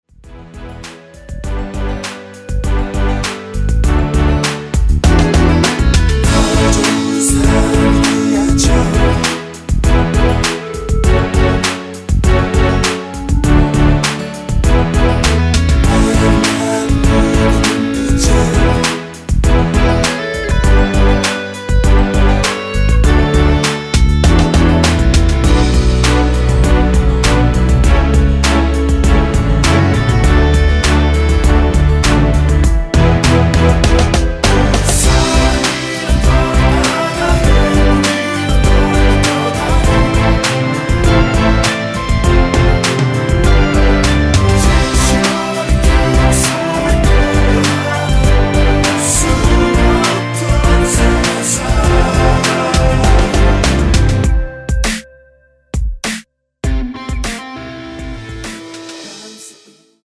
원키에서(-2)내린 멜로디와 코러스 포함된 MR입니다.(미리듣기 참조)
앞부분30초, 뒷부분30초씩 편집해서 올려 드리고 있습니다.